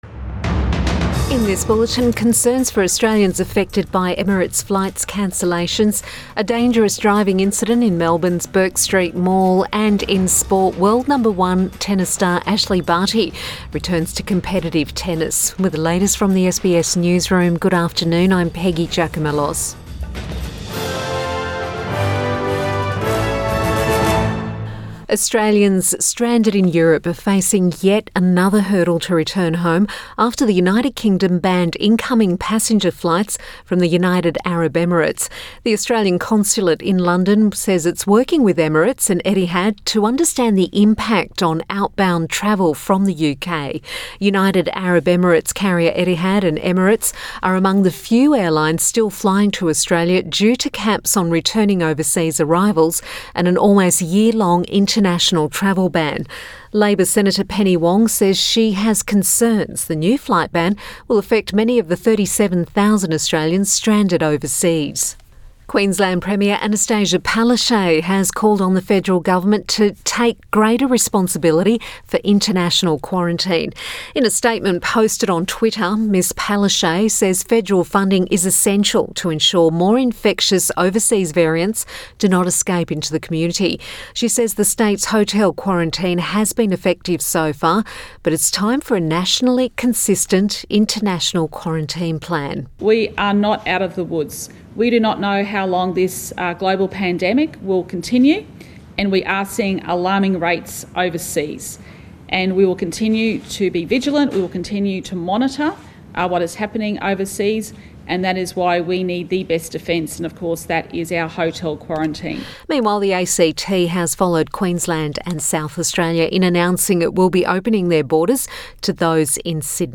Midday bulletin 29 January 2021